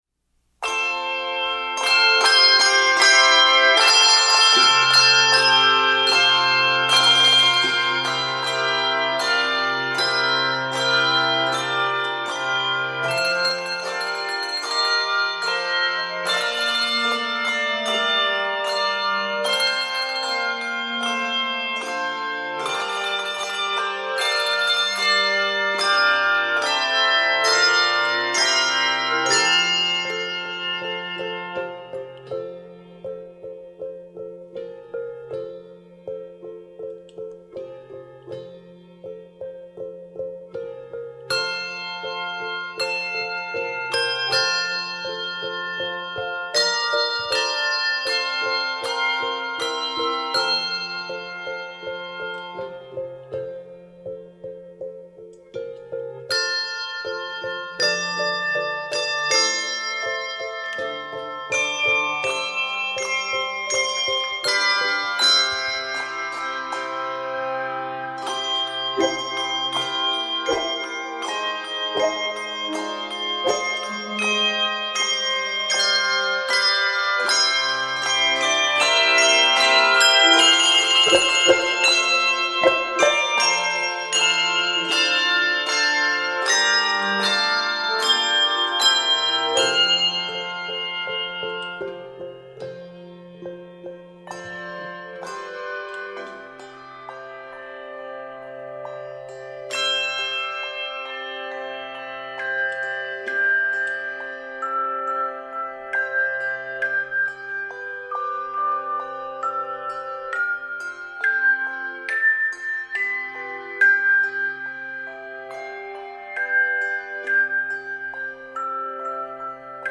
This spirited arrangement combines
six handchimes (D6-B6) add a shimmering effect to the melody